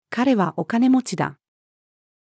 彼はお金持ちだ。-female.mp3